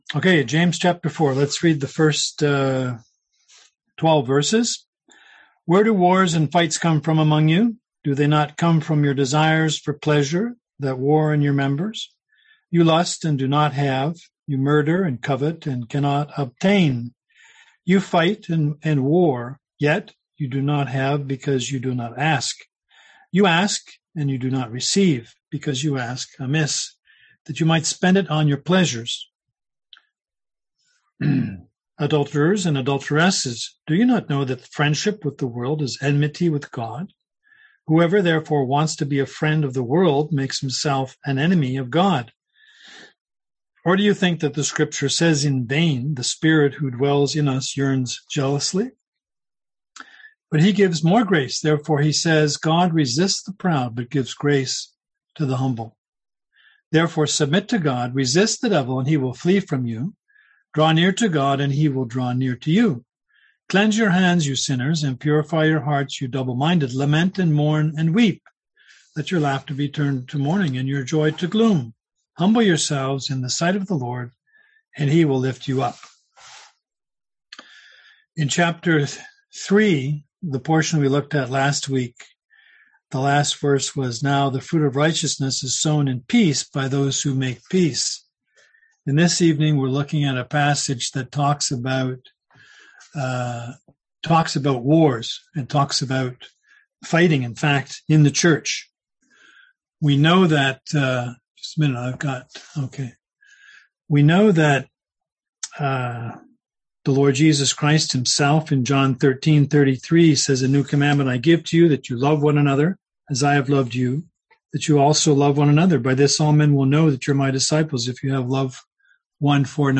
Service Type: Seminar Topics: Pride , Worldliness